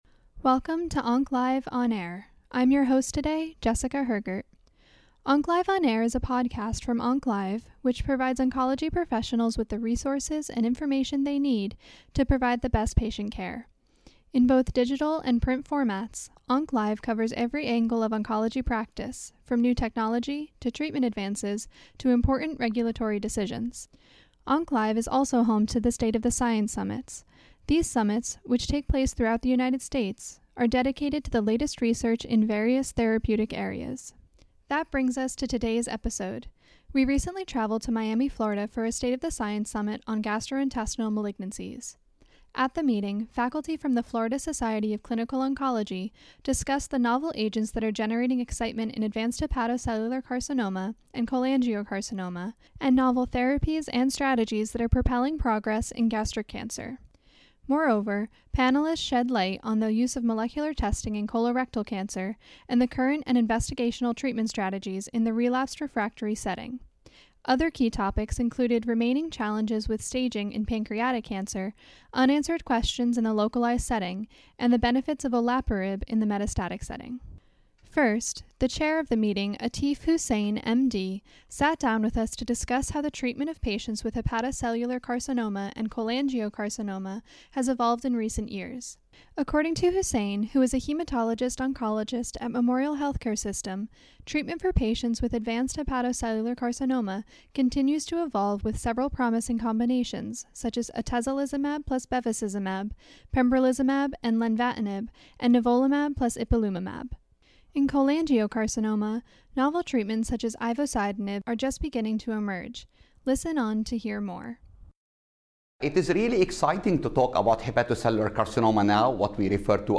We recently traveled to Miami, Florida for a State of the Science Summit™ on Gastrointestinal Malignancies. At the meeting, faculty from the Florida Society of Clinical Oncology discussed the novel agents that are generating excitement in advanced hepatocellular carcinoma and cholangiocarcinoma and the novel therapies and strategies that are propelling progress in gastric cancer. Moreover, panelists shed light on the use of molecular testing in colorectal cancer and the current and investigational treatment strategies in the relapsed/refractory setting.